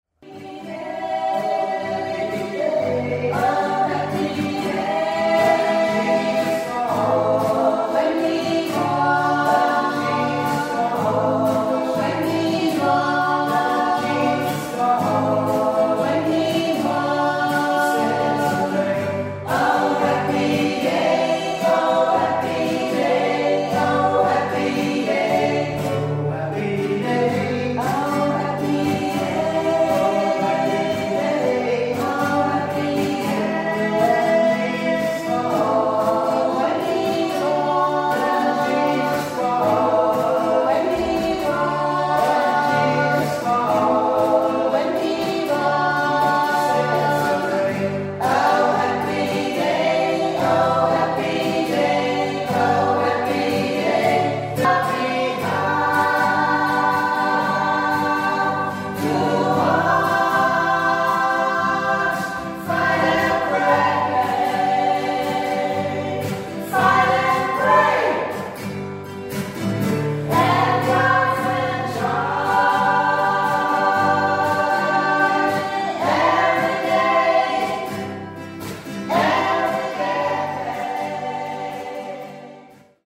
Die Blaue Kapelle im Laugnatal ist ein 12 m hoher Turmbau.
Schon beim Abstellen unserer Fahrräder hörten wir Gesang und Gitarrenmusik.
professionelle Gesang einer Gruppe von Frauen und Männern war in dem kleinen sakralen Raum sehr beeindruckend.
Die Akustik sei aber gerade in dieser Turmkapelle besonders gut.